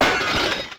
new_glass_break.ogg